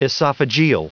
Prononciation du mot esophageal en anglais (fichier audio)
Prononciation du mot : esophageal